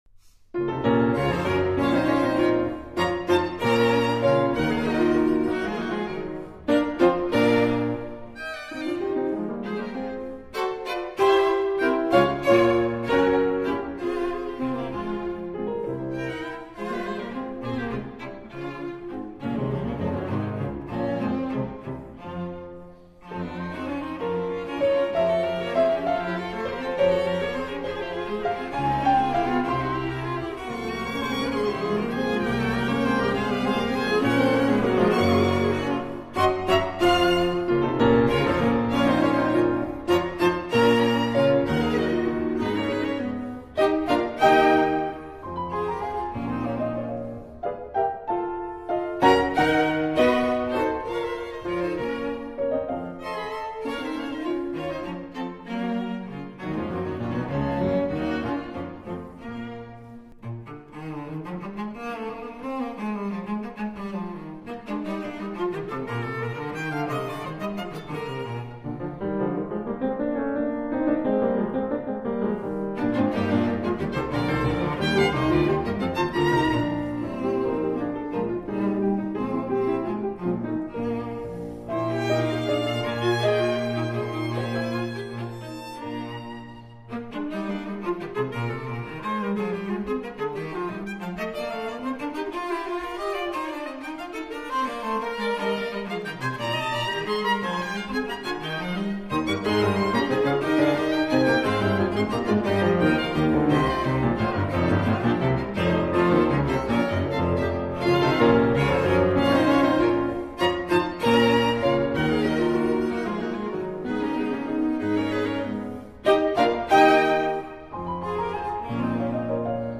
Soundbite 3rd Movtd